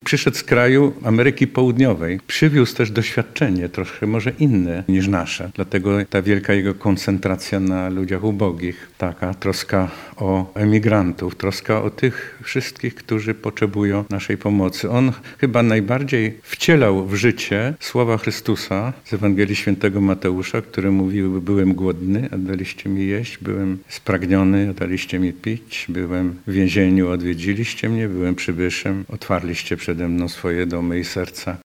Tak umarł jak żył, lubił zaskakiwać powiedział Radiu Lublin metropolita lubelski, arcybiskup Stanisław Budzik.